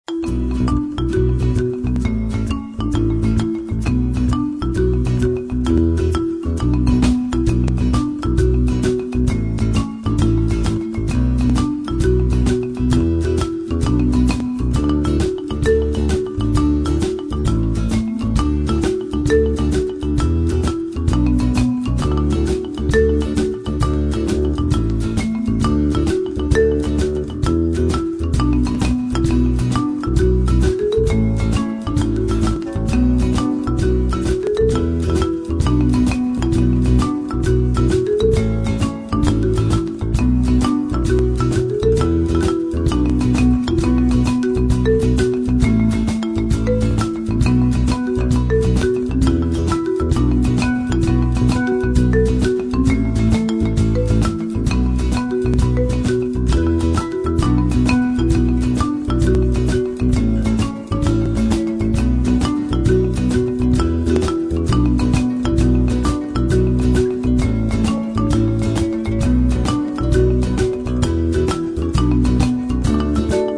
Blues  Celtic  Latin  Worldbeat
Featuring  marimba with full band
Irish tune
marimba, guitar, vocals, percussion